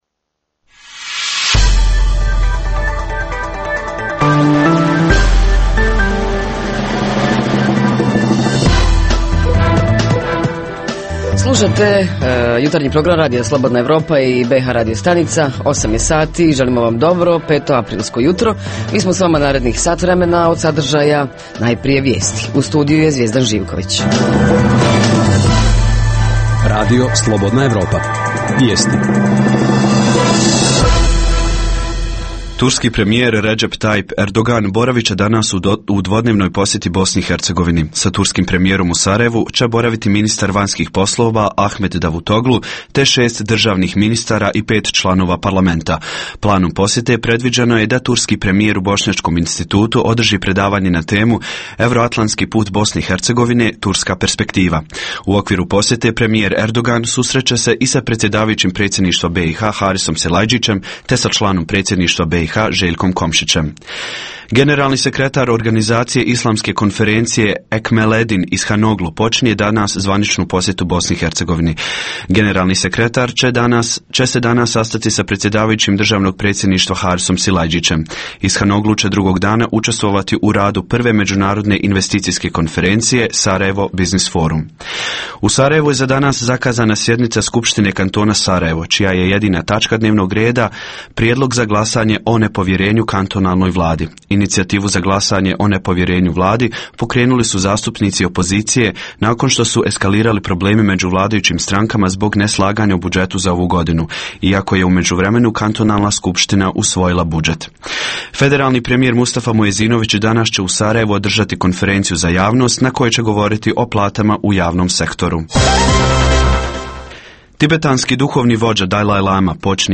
Jutarnji program za BiH koji se emituje uživo. Ponedjeljkom govorimo o najaktuelnijim i najzanimljivijim događajima proteklog vikenda.
Redovni sadržaji jutarnjeg programa za BiH su i vijesti i muzika.